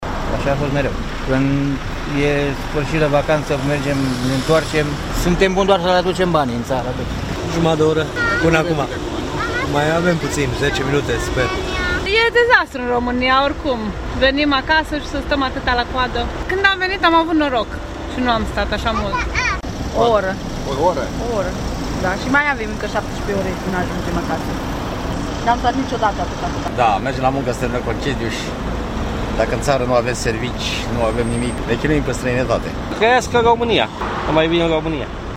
Oamenii au fost profund nemulțumiți de situația creată:
01-voxuri-critici-8.mp3